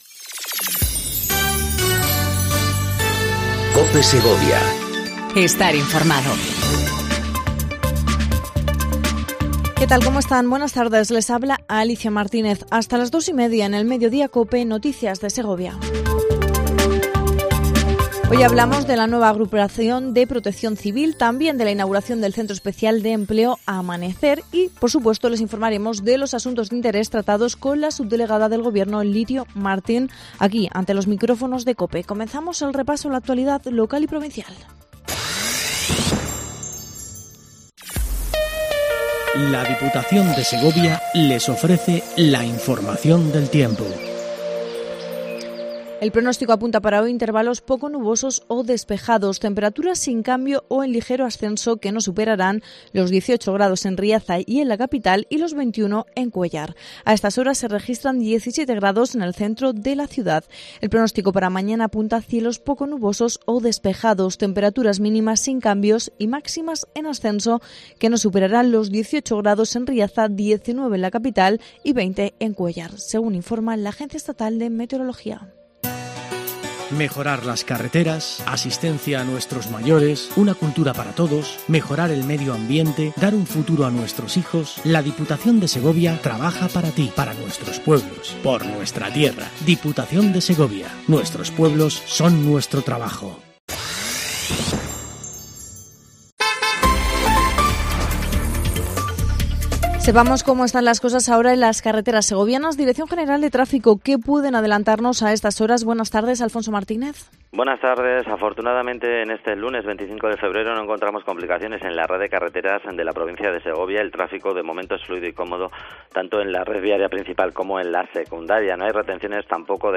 INFORMATIVO DEL MEDIODÍA EN COPE SEGOVIA 14:20 DEL 25/02/19